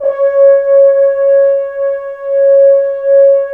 Index of /90_sSampleCDs/Roland LCDP06 Brass Sections/BRS_F.Horns 2 mf/BRS_FHns Dry mf